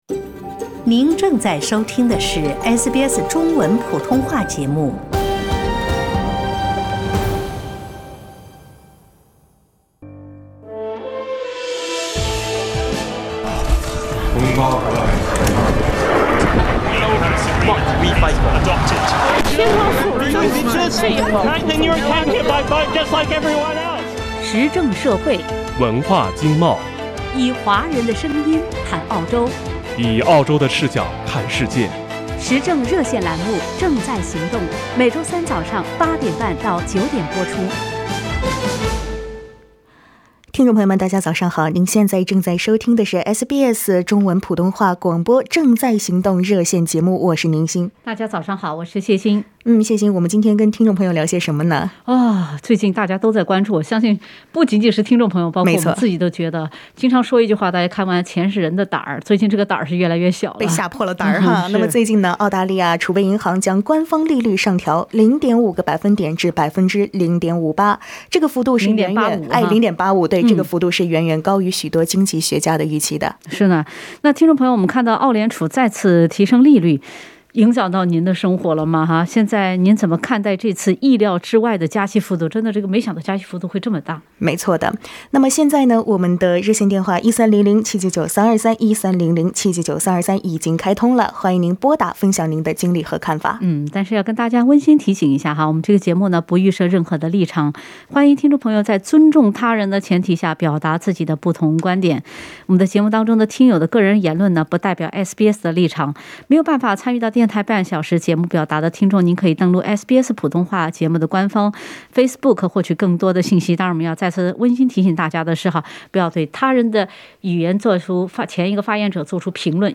热线听众仅代表其个人观点，不代表本台立场，仅供参考。